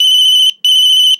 Tono de teléfono móvil 9